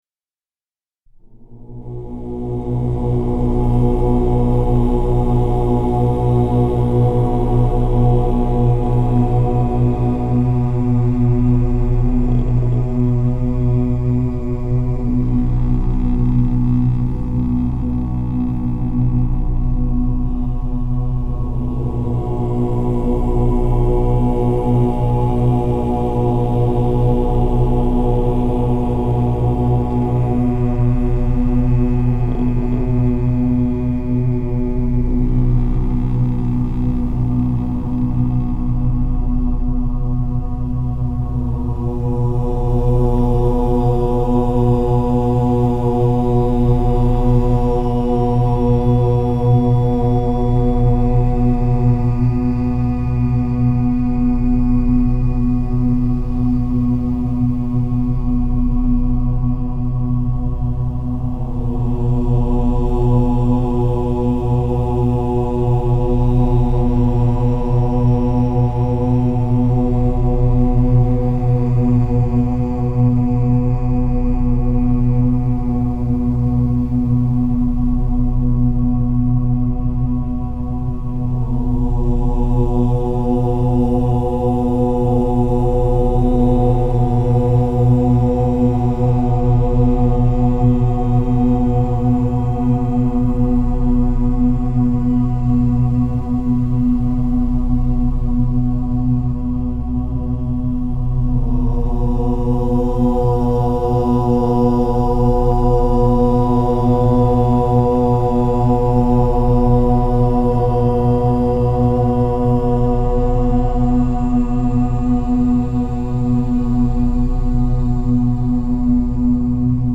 With Brainwave Entrainment